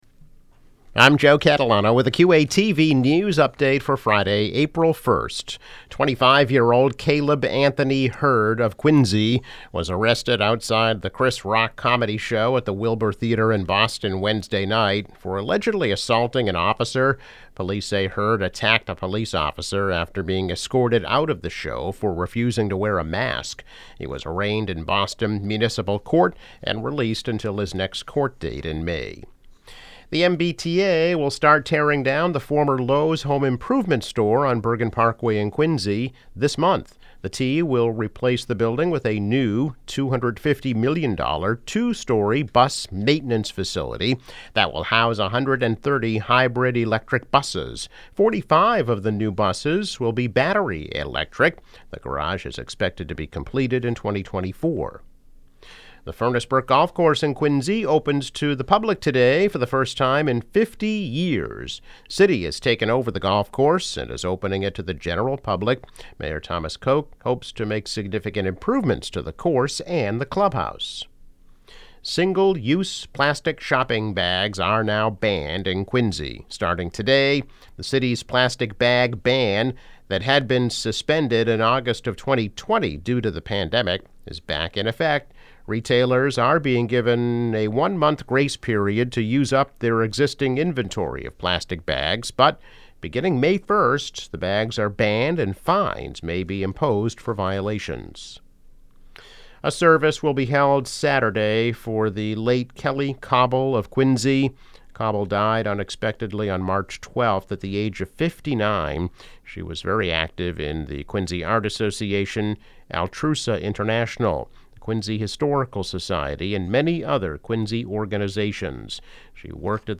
News Update - April 1, 2022